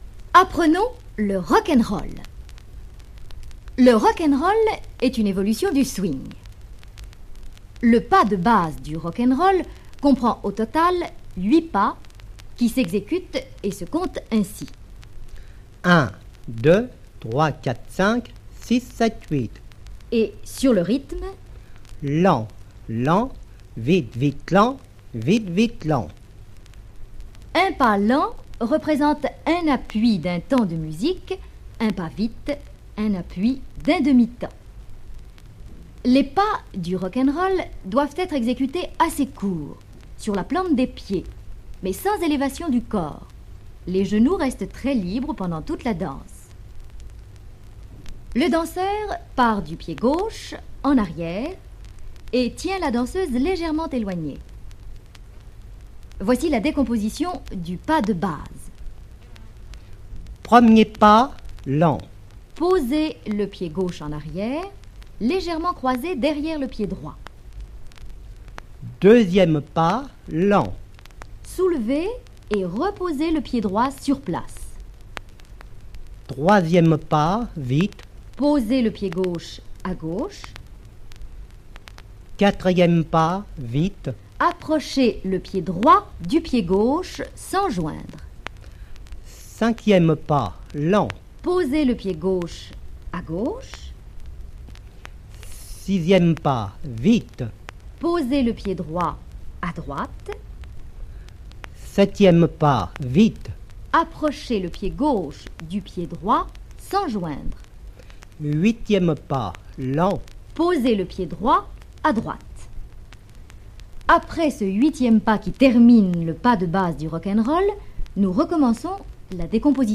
Expliquer à l'oral des pas de danse, ce n'est pas si simple mais, avec l'aide d'une assistante, visiblement plus jeune, il s'en sort plutôt bien pour cette leçon de plus de cinq minutes.
Avec sa voix légèrement chevrotante, c'est presque surréaliste.